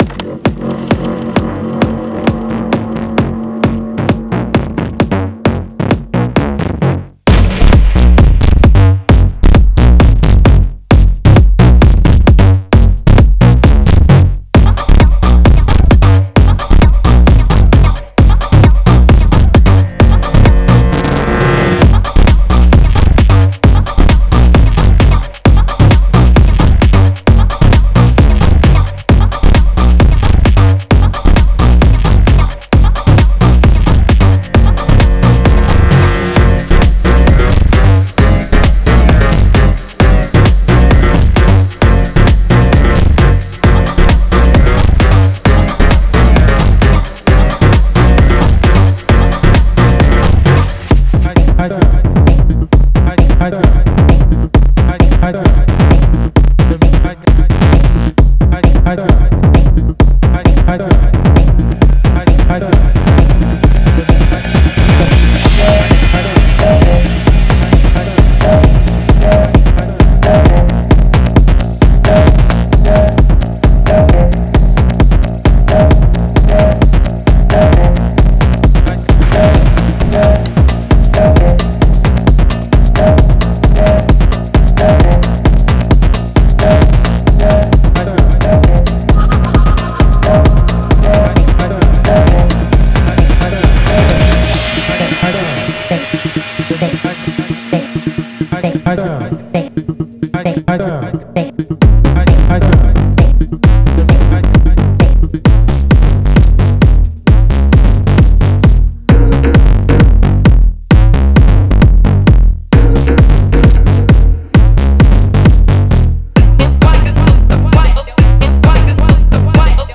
Genre:Techno
120以上のループには、唸るアナログベースラインや、幽霊のように漂う雰囲気のあるコードを収録しています。
デモサウンドはコチラ↓
95 FX (Foley, Impacts, Synth FX, Drones)
60 Vocal Chops